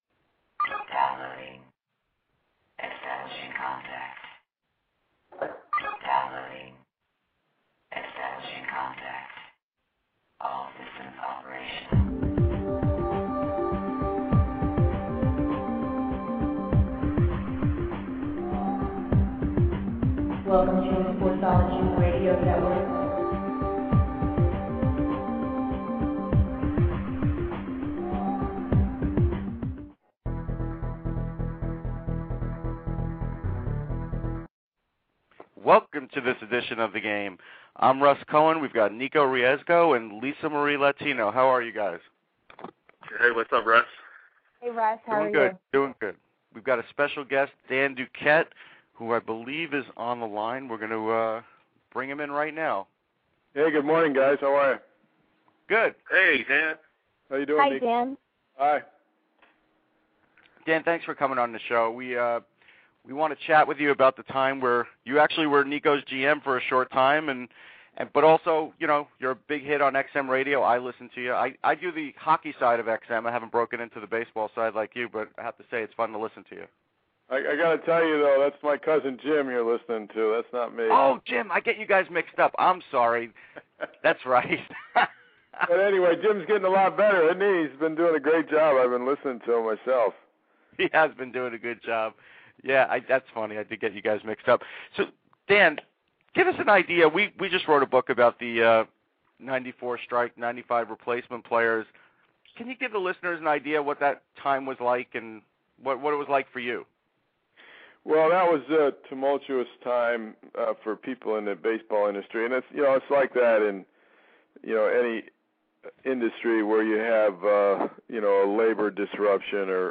Our special guest this week is Dan Duquette.